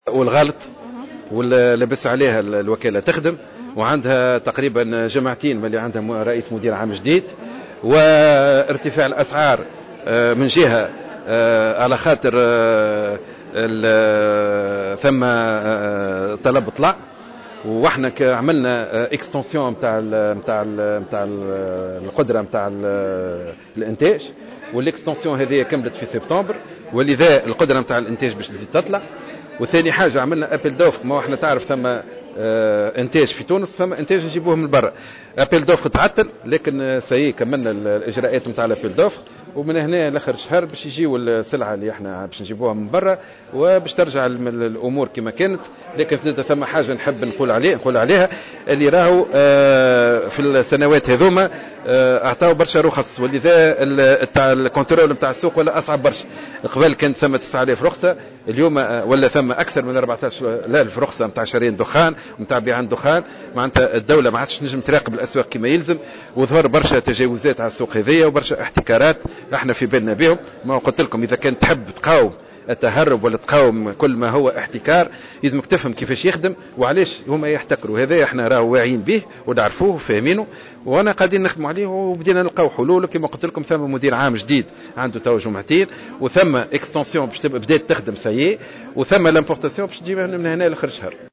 Le ministre des finances, Slim Chaker, a annoncé, lors d’une conférence organisée au palais gouvernemental de La kasbah pour présenter le projet de loi du budget 2016, l’annulation du timbre de 60 dinars imposé aux voyageurs tunisiens.